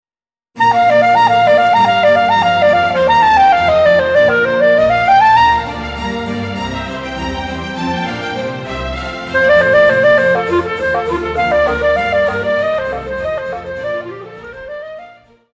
古典
降B調單簧管
管弦樂團
演奏曲
僅伴奏
沒有主奏
沒有節拍器